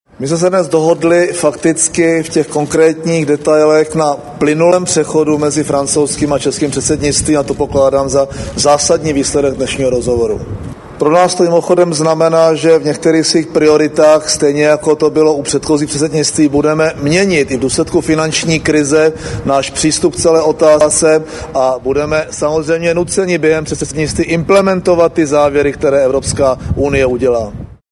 Ujistil, že neplánuje žádné pravidelné summity zemí eurozóny v době českého předsednictví. (Uvádíme zvukový záznam proslovu premiéra M. Topolánka)